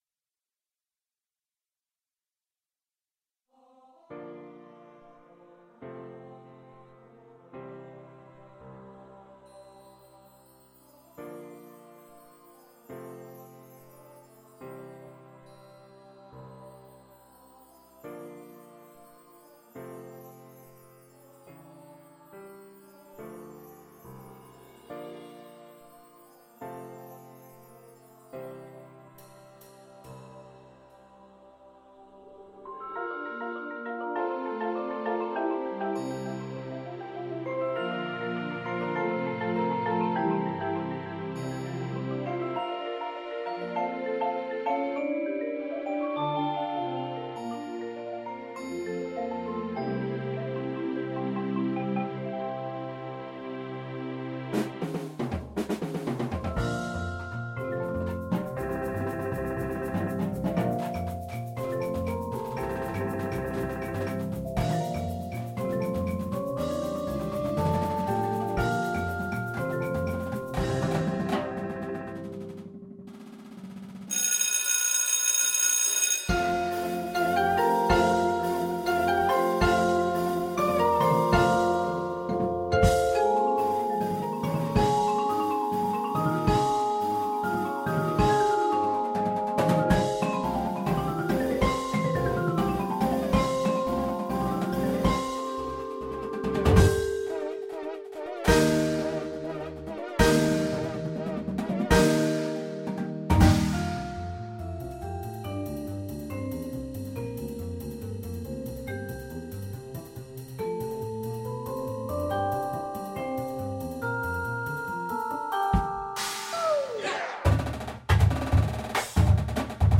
contemporary Indoor Percussion Show